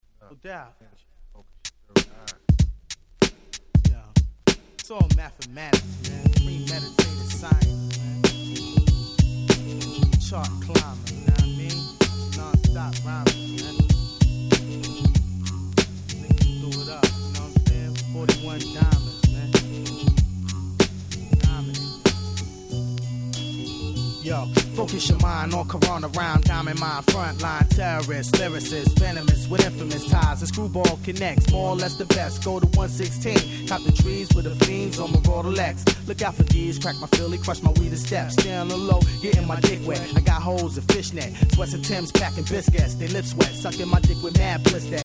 98' Nice Street Hip Hop !!
も相変わらずのHard Core Shitでナイス！